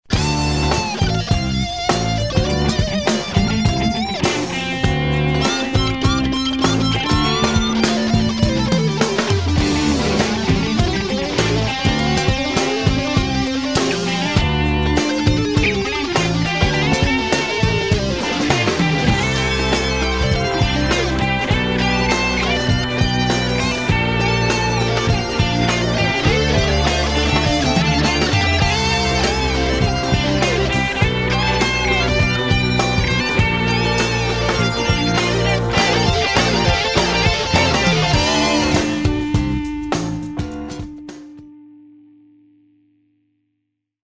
Jam tracks -- nahrajte si vlastne gitarove stopy a zverejnite!